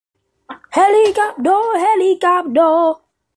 Helicopter Téléchargement d'Effet Sonore
Helicopter Bouton sonore